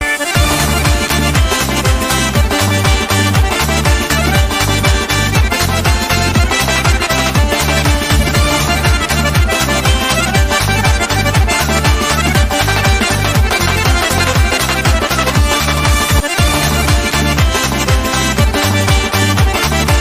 Супер-лезгинка (короткий отрывок)